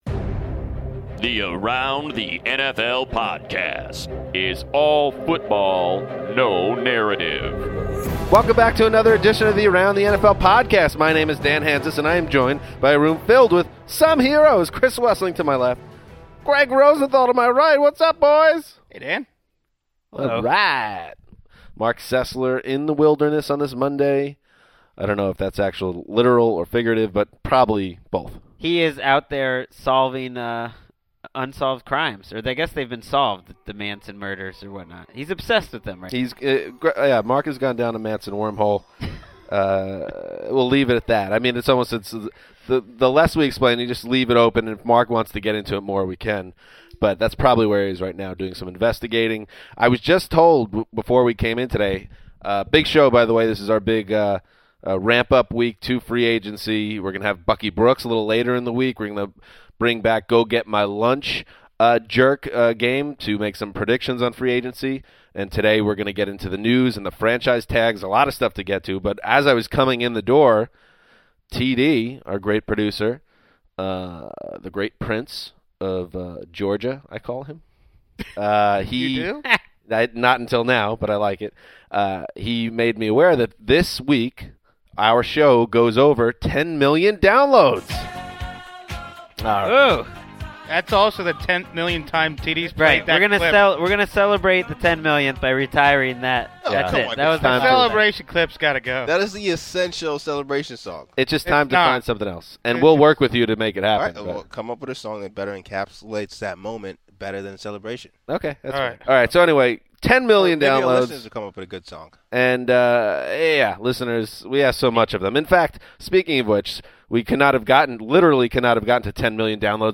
calls in to discuss which teams are going to be big spenders this offseason.